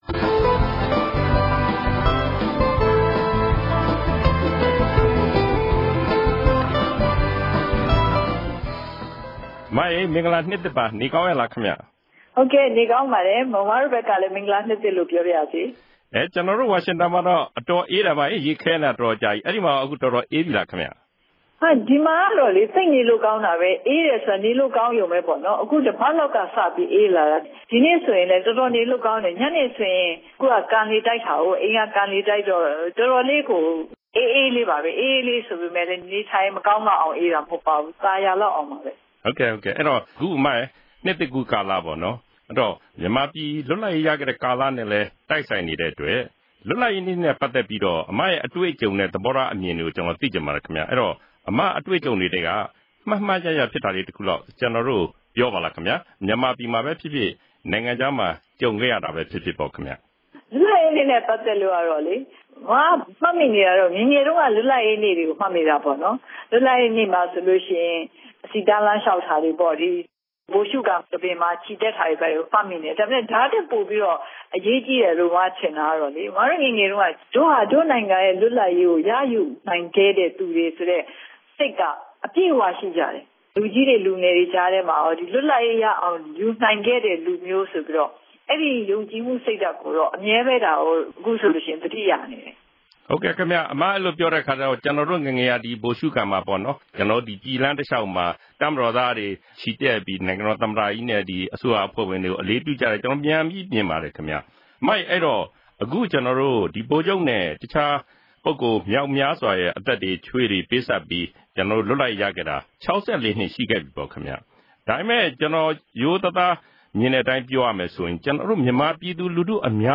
၂ဝ၁၂ ခုနှစ်အတွက် အာအက်ဖ်အေရဲ့ အစီအစဉ်သစ်မှာ မြန်မာ့ ဒီမိုကရေစီ ခေါင်းဆောင် ဒေါ်အောင်ဆန်းစုကြည် က သူ့ရဲ့ ဘဝ အတွေ့အကြုံတွေနဲ့ ဒီမိုကရေစီရေး သဘောထားတွေ အတွေးအခေါ်တွေ အကြောင်းကို ထုတ်ဖော် ပြောကြားသွားပါတယ်။